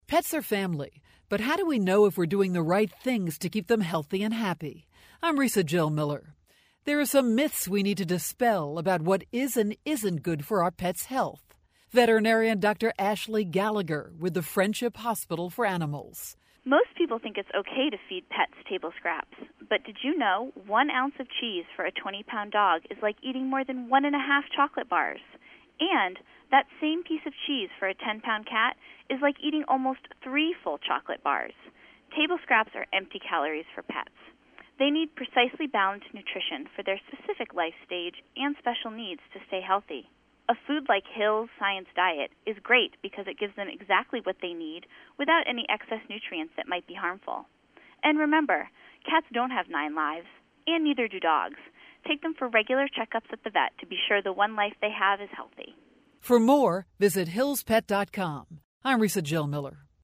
April 1, 2013Posted in: Audio News Release